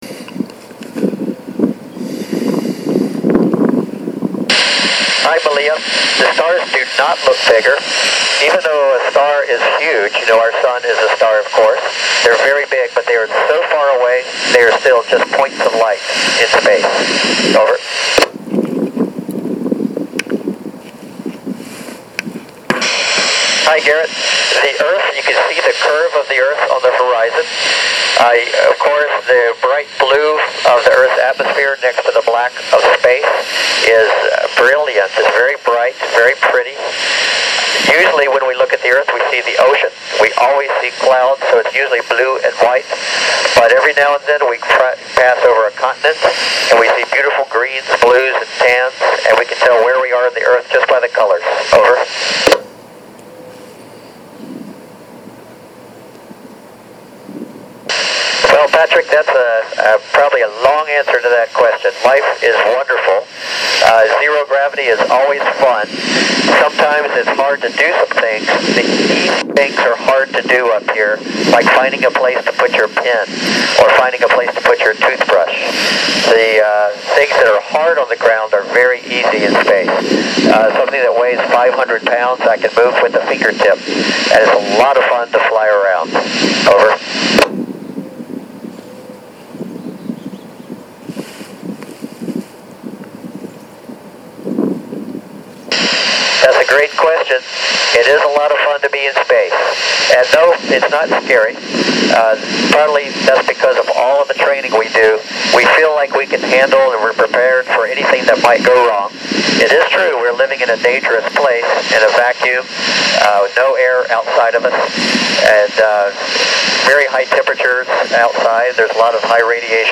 Flight Engineer Tom Marshburn taking questions from the group at K4UAH. This pass was at 14 degrees to the west of central Pennsylvania and recorded using a Yaesu FT-60R handheld on an Arrow 146-437 antenna.